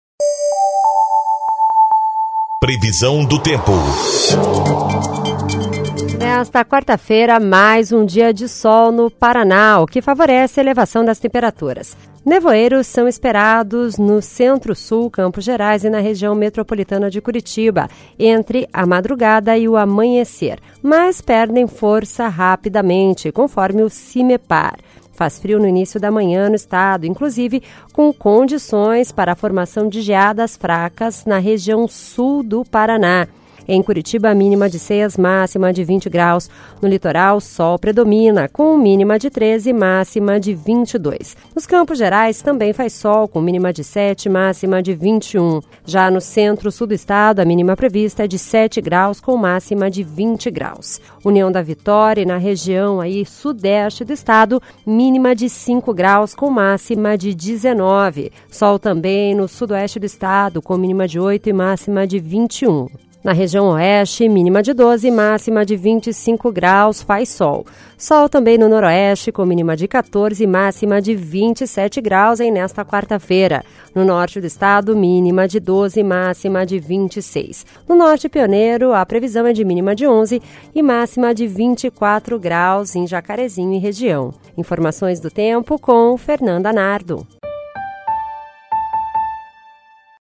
Previsão do tempo (05/07)